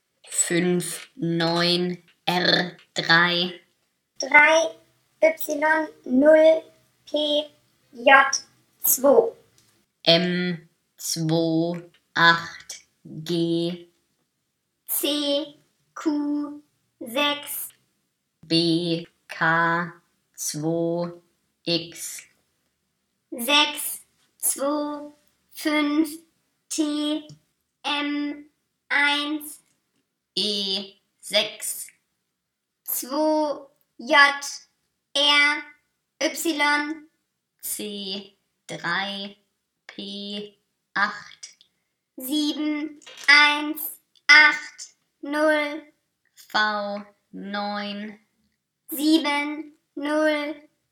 Das Gespräch begann mit einer Eins, dann kam ein Knacken und es ging wie folgt weiter:
Mitschnitt